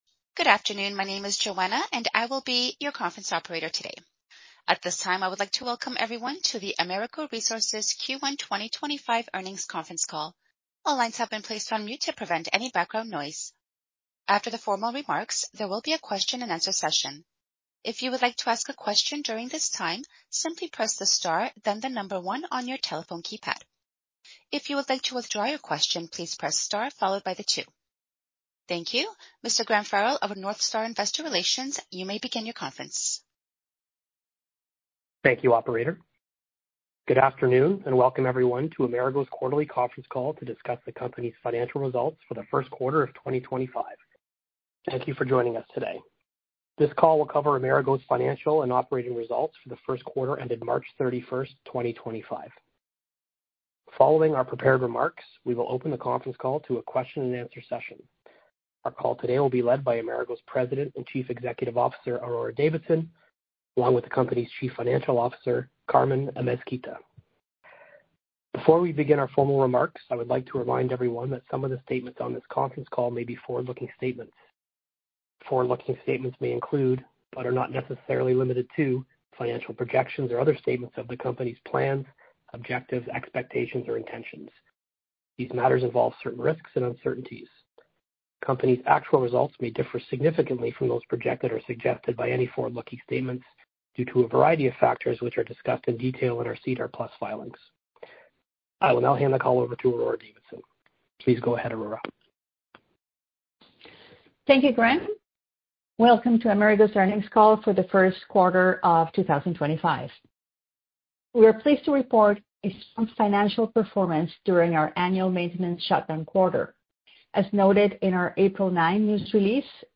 Investor Conference Calls
Amerigo-Resources-Q1-2025-Earnings-Call.mp3